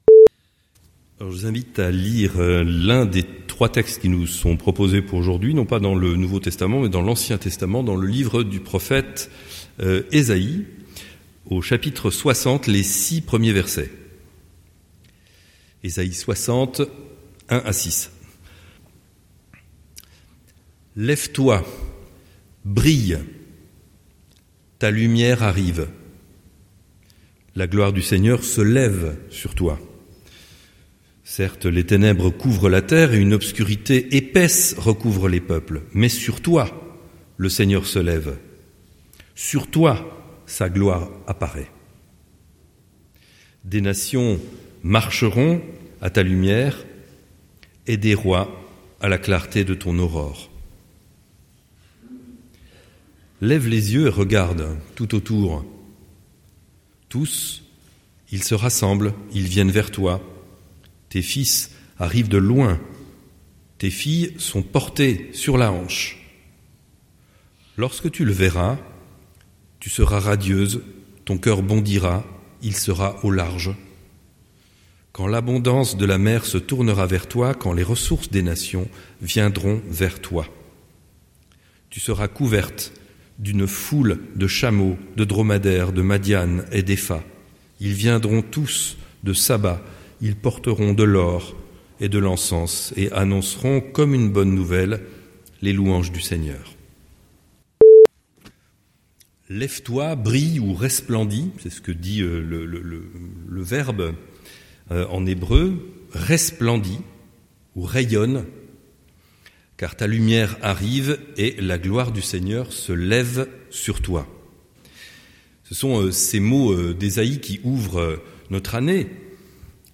Prédication du 04/01/2026